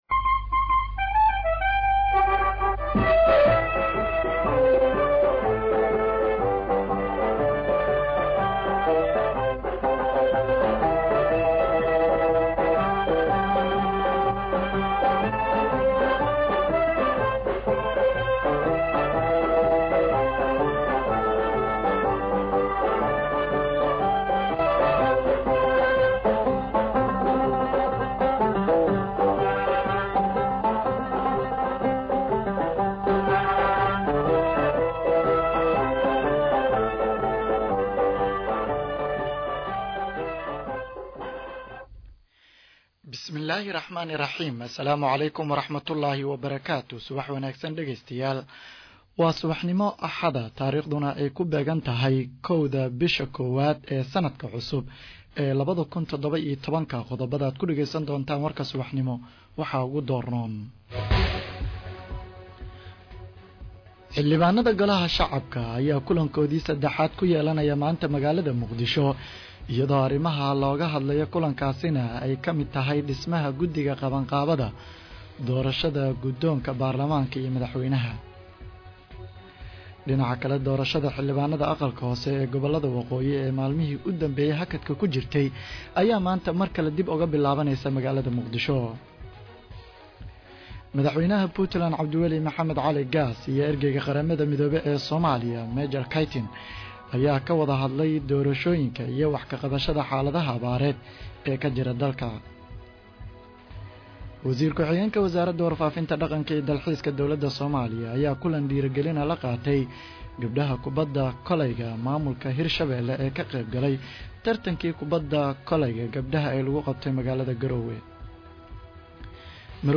Dhageyso Warka Subax Ee Radio Muqdisho 1-1-2017